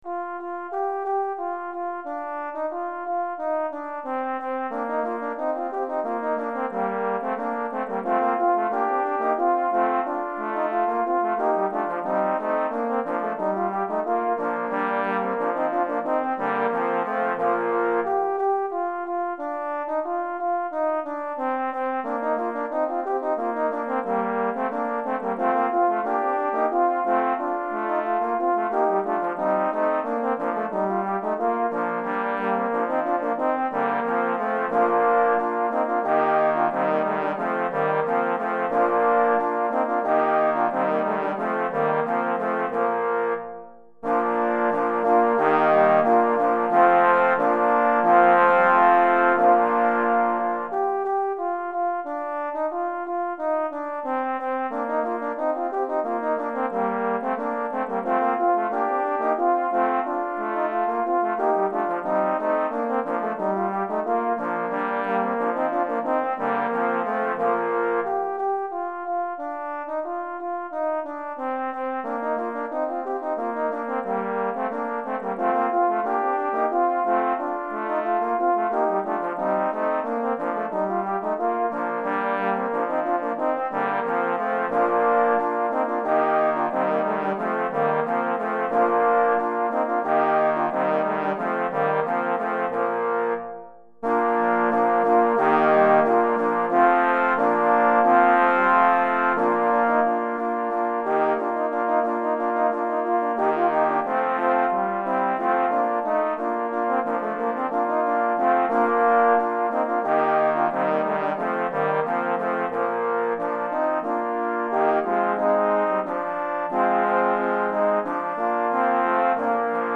6 Trombones 2 Trombone Basses